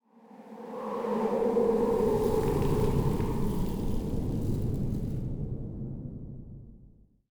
housewind12.ogg